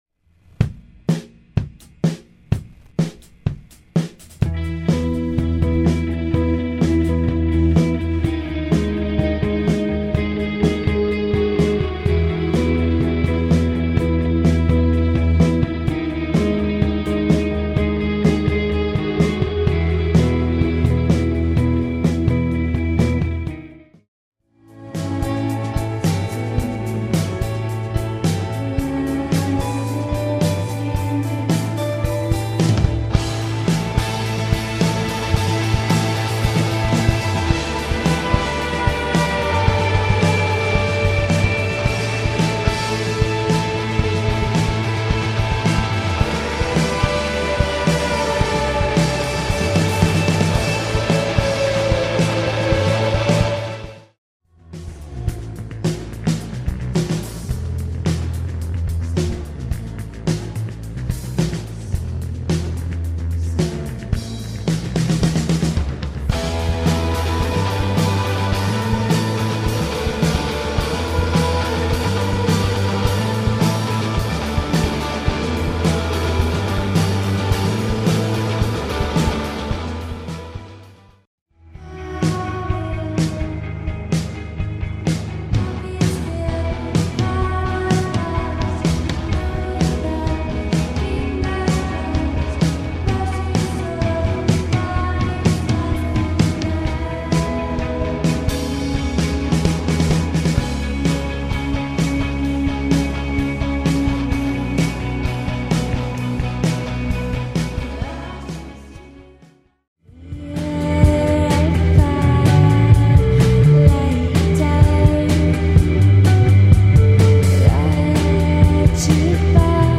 From various shows in San Francisco and Los Angeles.